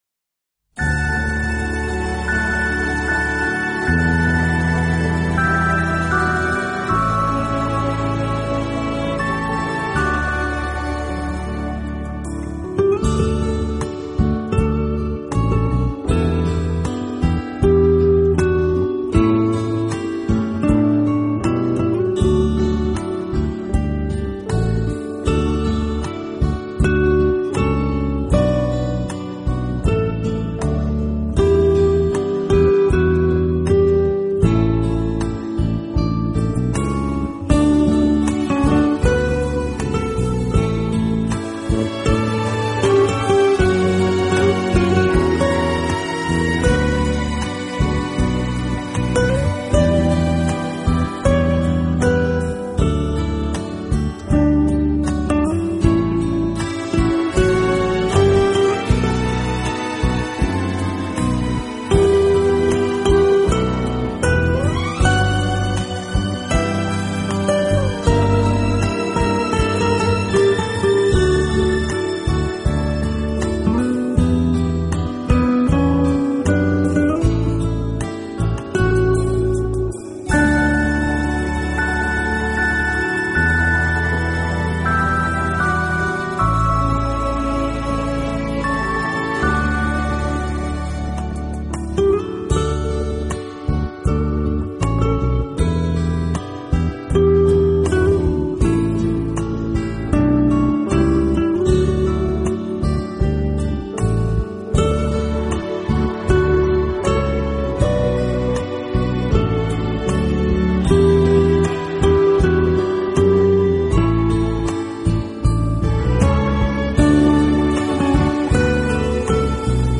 轻音吉他